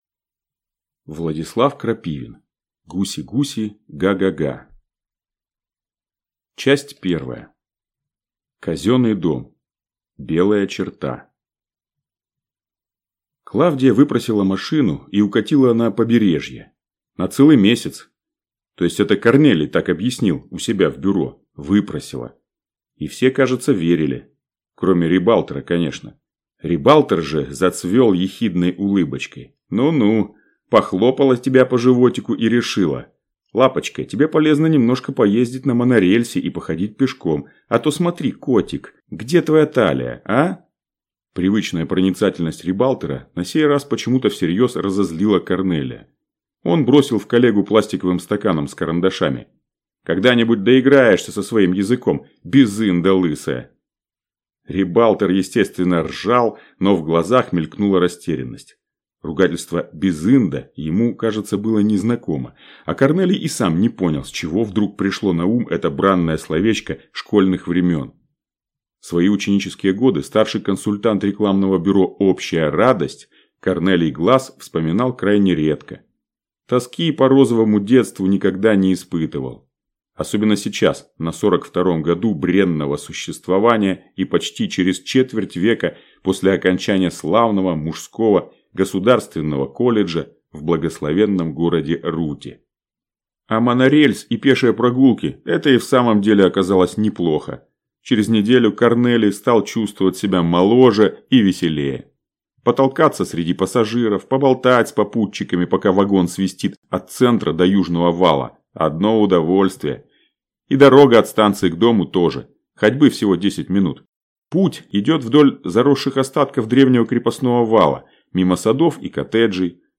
Аудиокнига Гуси-гуси, га-га-га…